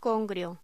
Locución: Congrio
voz